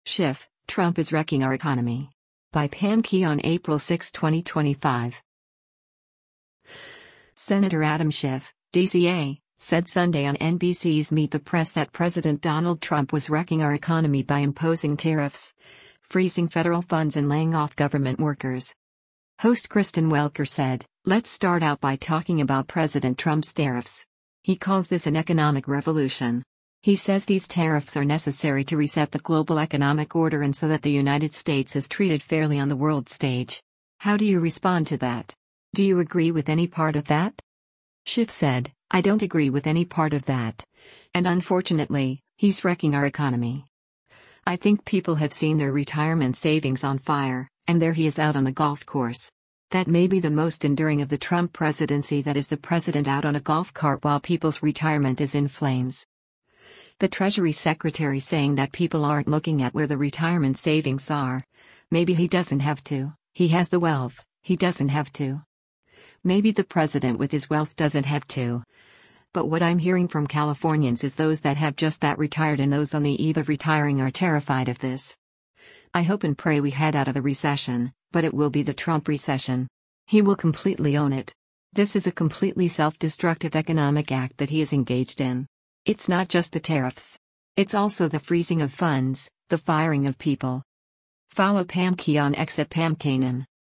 Senator Adam Schiff (D-CA) said Sunday on NBC’s “Meet the Press” that President Donald Trump was “wrecking our economy” by imposing tariffs, freezing federal funds and laying off government workers.